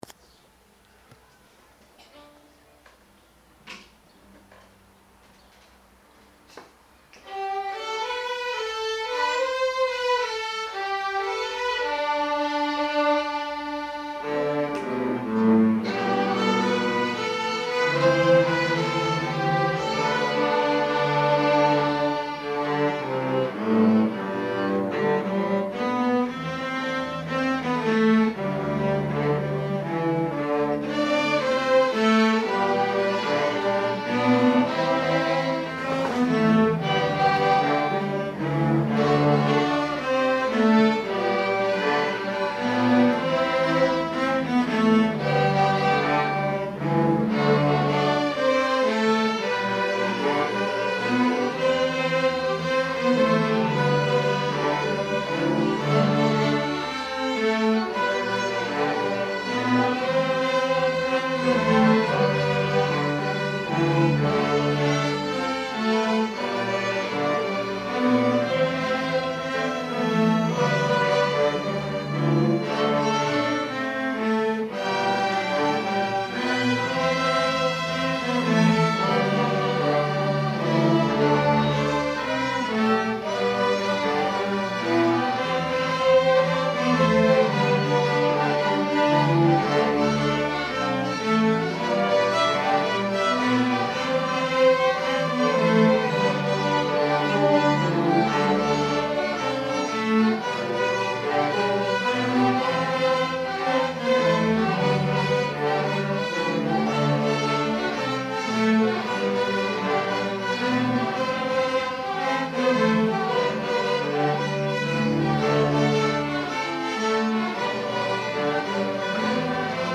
Para una plantilla instrumental compuesta por: Violín I Violín II Viola Violonchelo Contrabajo - Extensión: 79 compases - Compás: 4/4. - Tonalidad: Sol Mayor Interpretaciones en directoaudio/mpeg
Toma_de_ensayo_Wimoweh.MP3